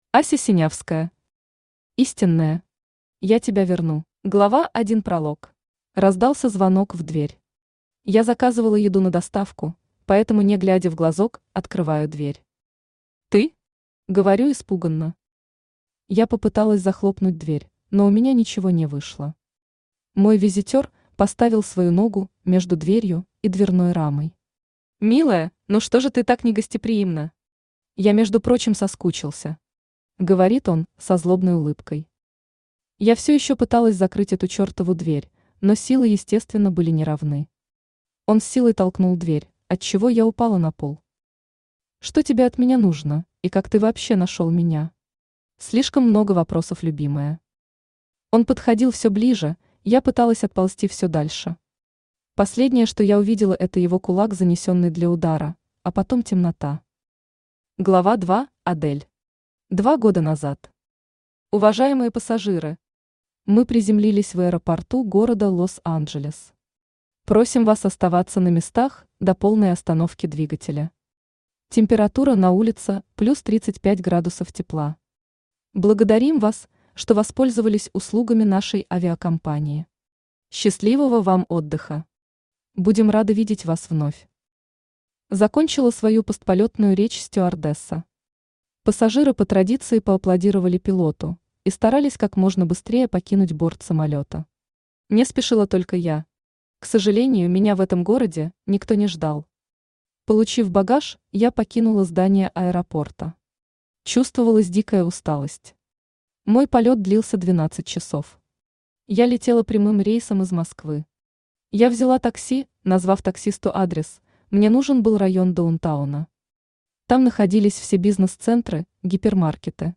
Аудиокнига Истинная. Я тебя верну | Библиотека аудиокниг
Я тебя верну Автор Ася Синявская Читает аудиокнигу Авточтец ЛитРес.